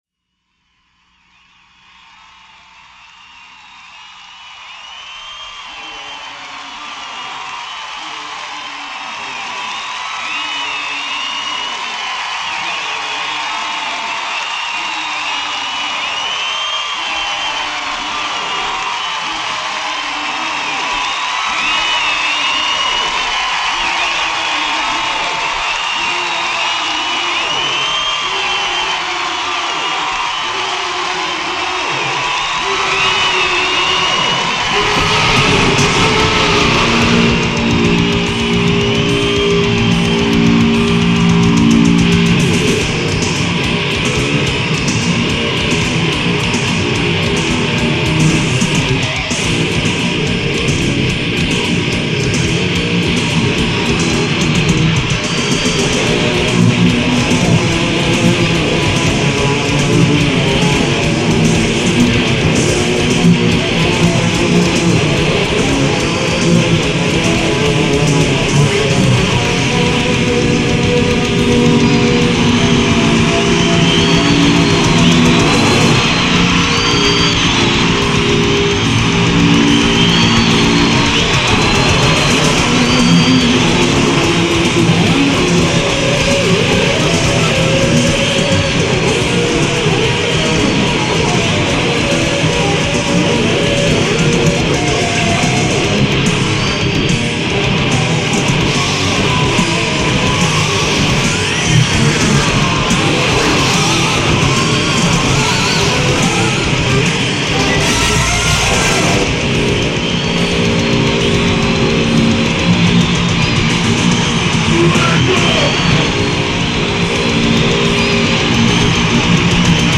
＊ボリューム注意
DRUMは打ち込み。